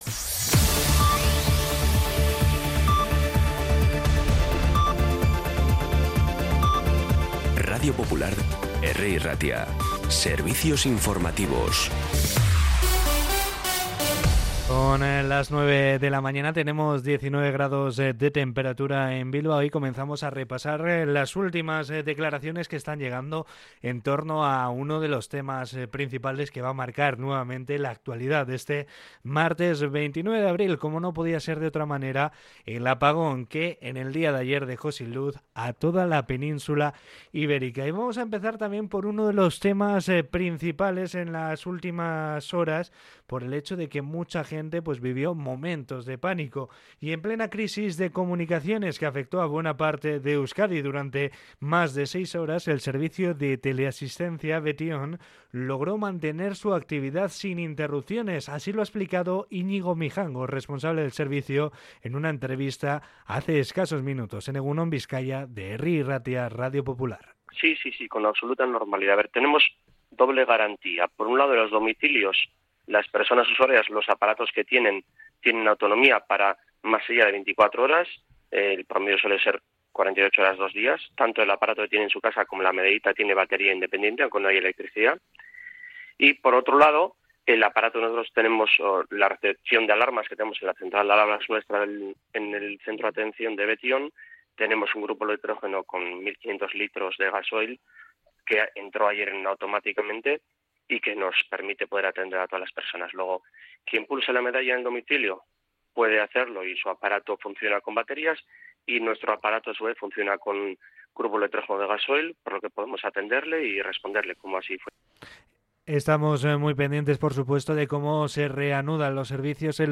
Las noticias de Bilbao y Bizkaia del 29 de abril a las 9
Los titulares actualizados con las voces del día.